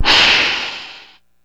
Index of /90_sSampleCDs/E-MU Producer Series Vol. 3 – Hollywood Sound Effects/Water/Alligators
GATOR HISS-R.wav